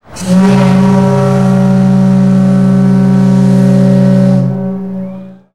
titanicwhistle.wav